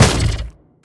biter-roar-behemoth-2.ogg